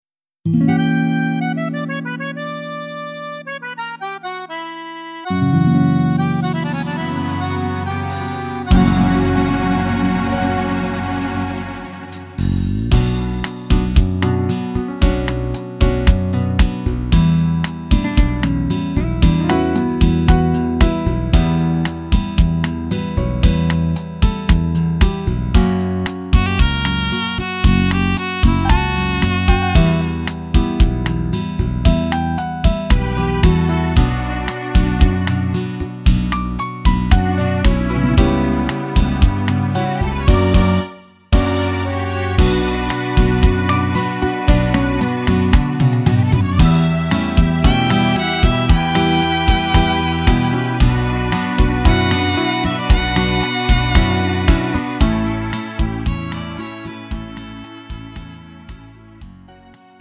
음정 -1키 3:57
장르 구분 Lite MR